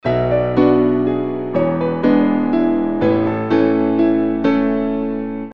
Рингтоны без слов
Пианино , Инструментальные